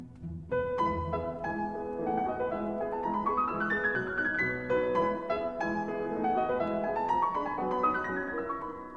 Mi Majeur. 2/4